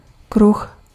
Ääntäminen
France (Paris): IPA: [ɛ̃ disk]